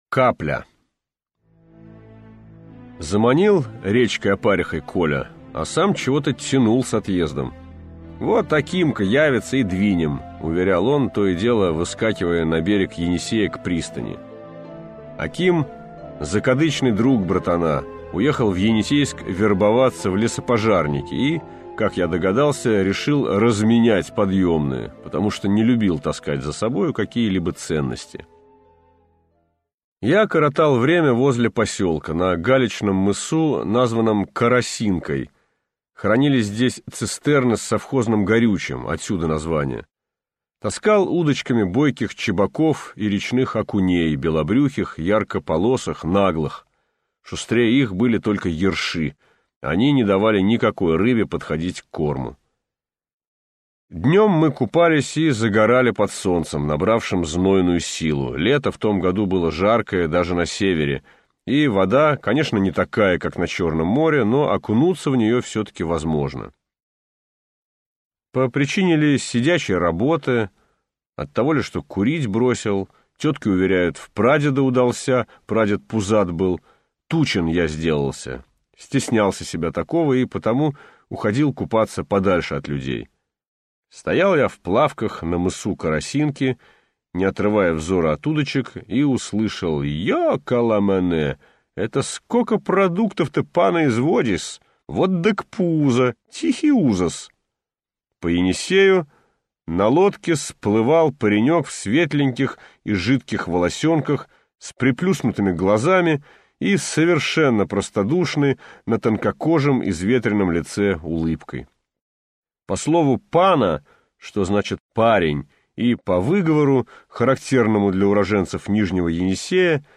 Аудиокнига Царь-рыба | Библиотека аудиокниг